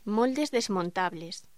Locución: Moldes desmontables
voz